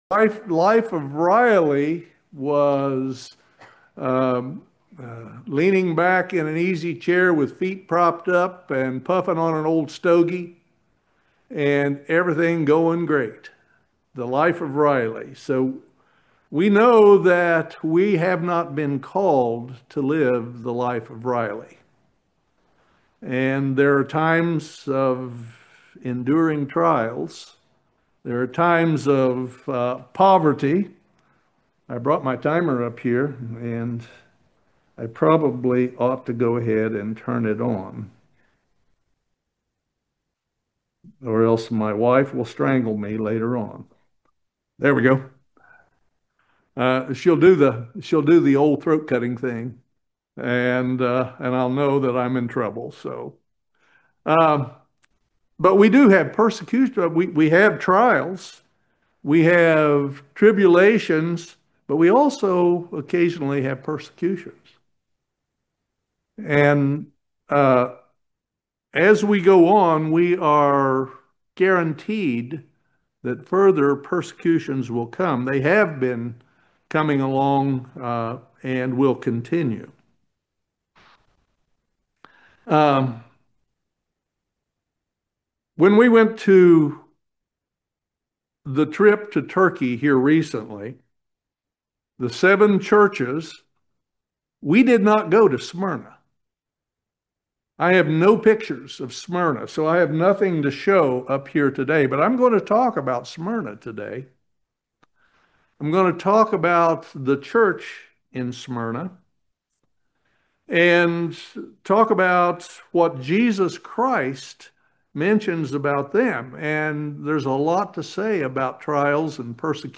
Join us for this excellent sermon about the church in Smyrna. The second sermon on the 7 churches of Revelation.
Given in Lexington, KY